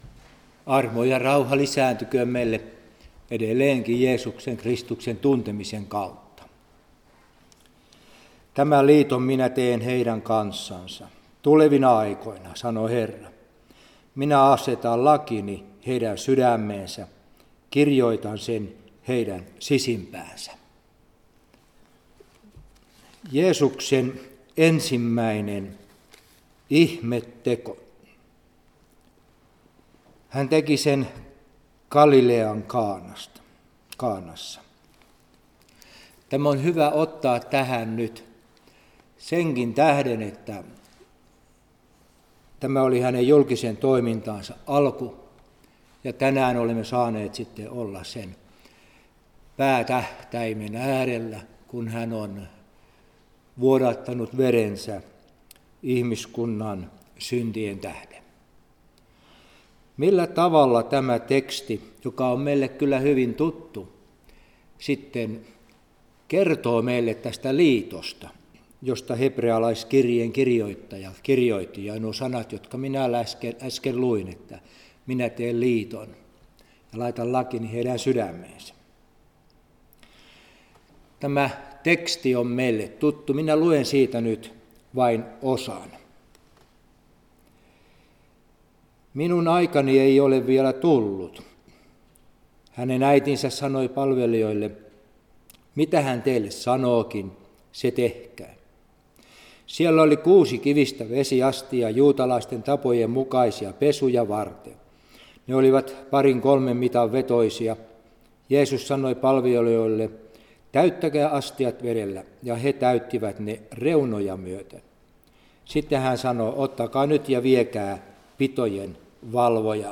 Ristinjuhla Soinissa